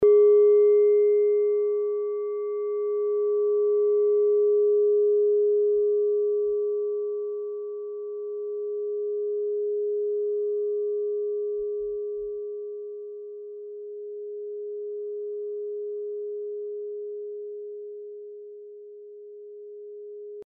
Klangschale Nepal Nr.33
Klangschale-Durchmesser: 14,8cm
(Ermittelt mit dem Filzklöppel)
Der Uranuston liegt bei 207,36 Hz und ist die 39. Oktave der Umlauffrequenz des Uranus um die Sonne. Er liegt innerhalb unserer Tonleiter nahe beim "Gis".
klangschale-nepal-33.mp3